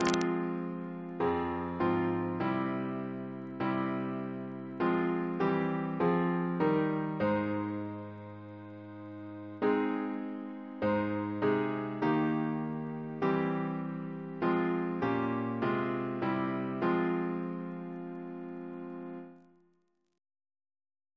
Double chant in F minor Composer: Richard Clark (1786-1856), Vicar Choral of St. Paul's Reference psalters: ACB: 370; ACP: 23; H1982: S219